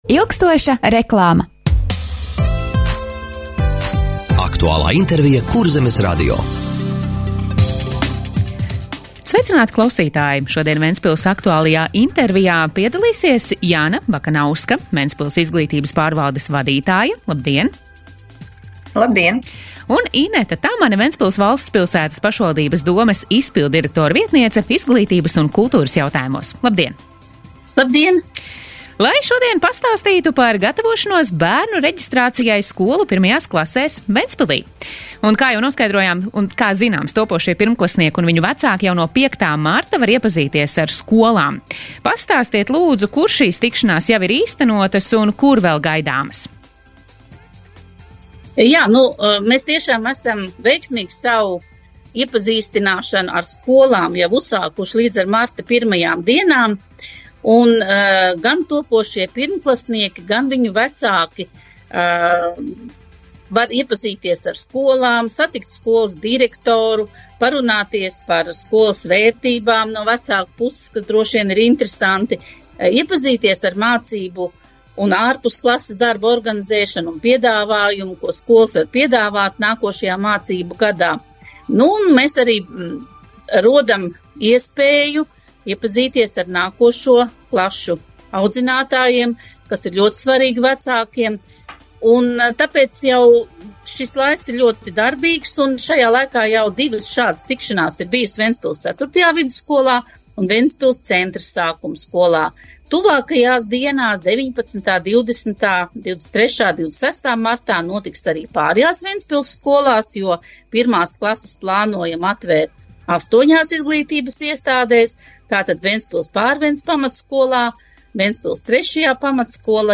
Radio saruna Ventspilī notiek gatavošanās bērnu reģistrācijai skolu 1. klasēs - Ventspils